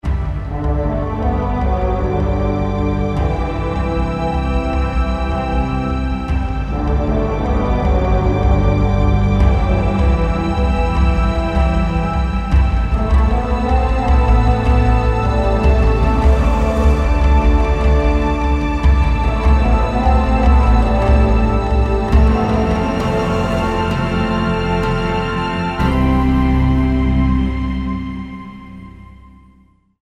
Soundtrack with an African Feel!
Tribal drums, moving panoramic
themes and atmospheres with beautiful African voices